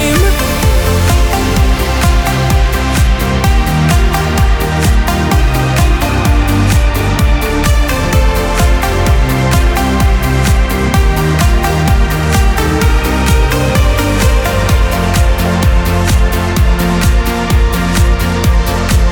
• Качество: 242, Stereo
зажигательные
Electronic
EDM
без слов
энергичные
Big Room
electro house
Приятная клубная музыка